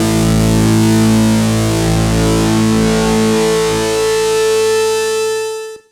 gtdTTE67003guitar-A.wav